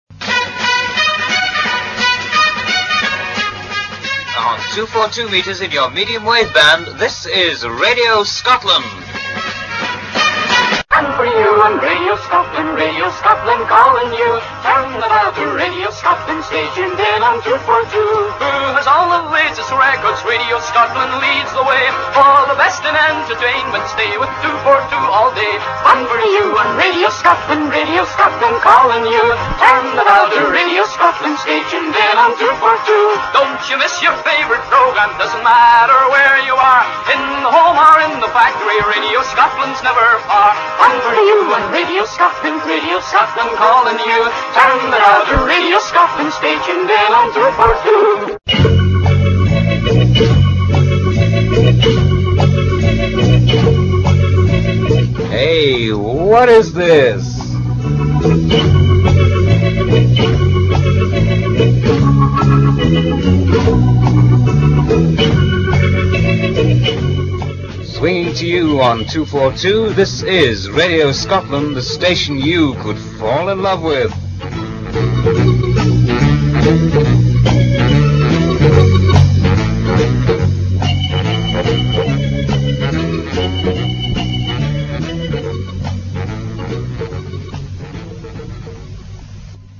From it, here is an early station identification, a jingle and a station promotion. The voice belongs to DJ Roger Gale (duration 1 minute 29 seconds)